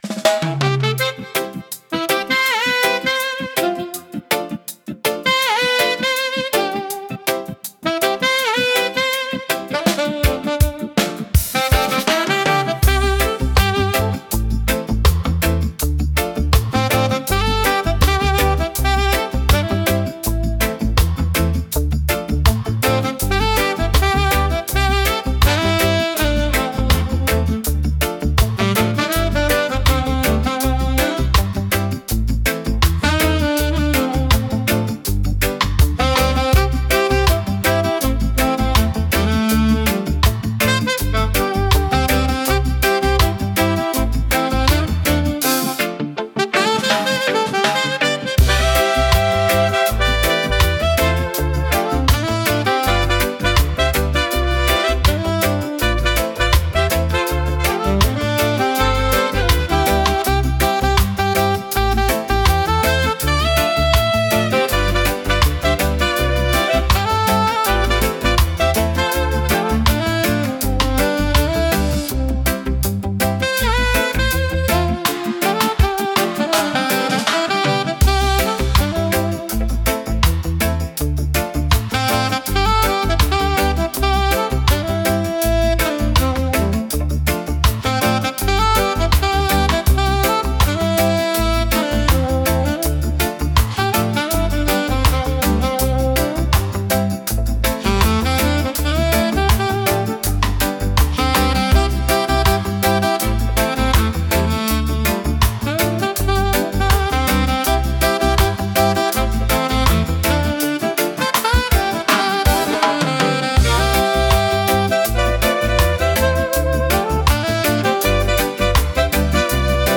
レゲエ特有のベースラインとドラムパターンが心地よいグルーヴを生み出します。
ナチュラルで陽気な雰囲気を演出し、聴く人に穏やかで楽しい気分をもたらします。